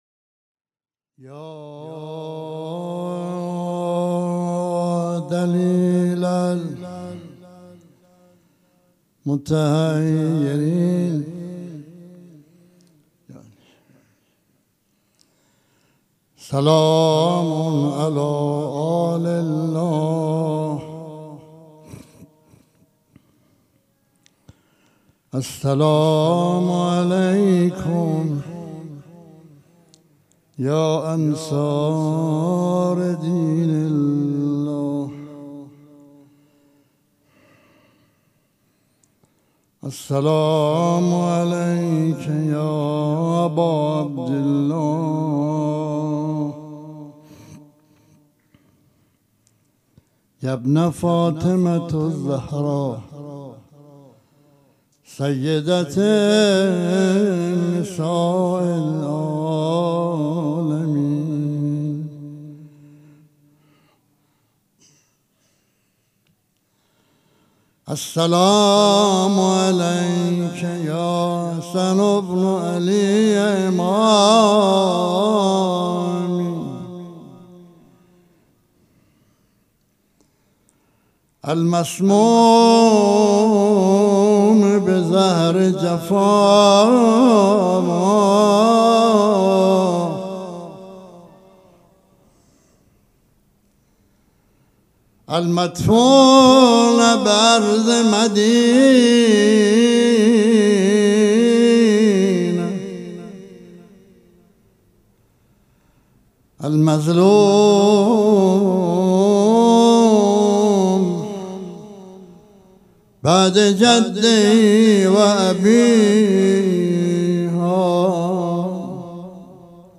در ادامه صوت مداحی این افراد را می‌شنوید.